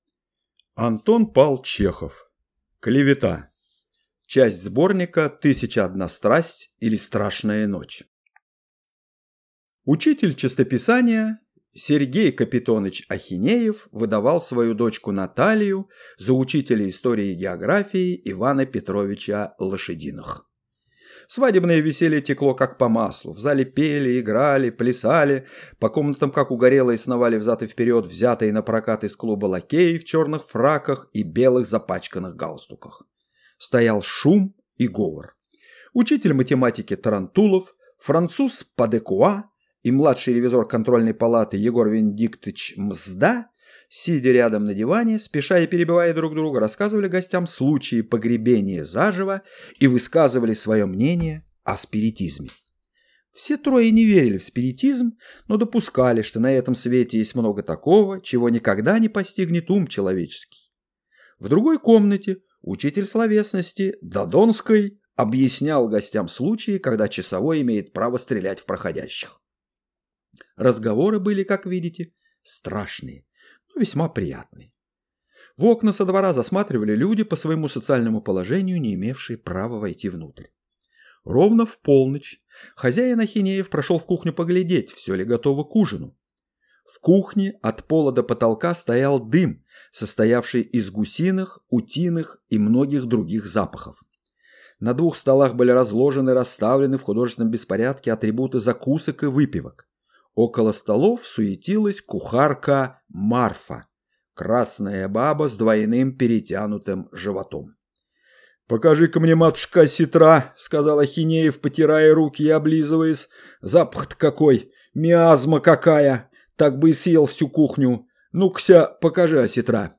Аудиокнига Клевета | Библиотека аудиокниг